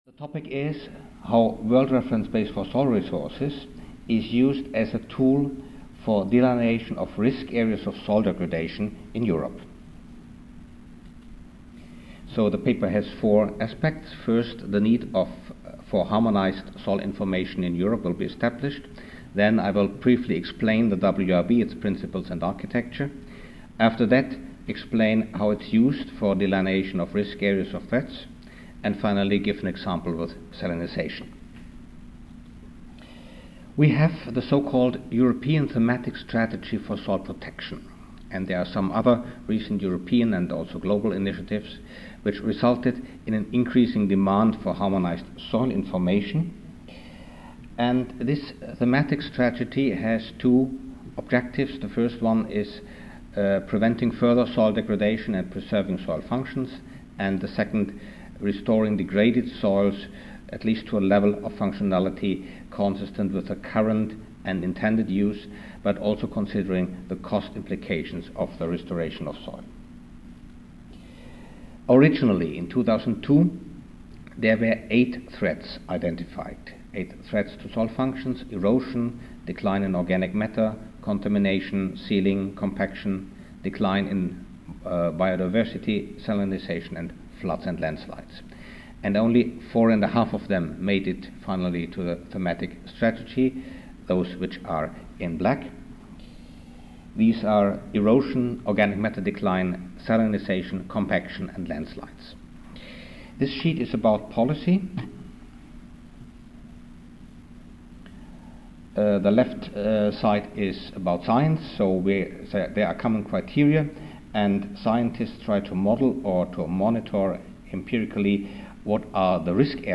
The World Reference Base for Soil Resources (WRB) as a Tool for Delineation of Risk Areas of Soil Degradation in Europe. Recorded presentation